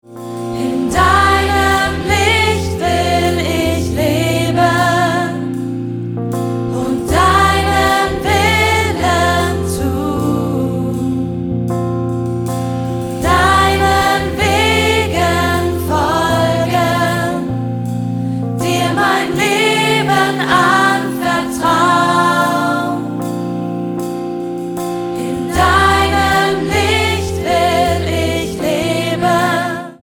100 Sänger und Live-Band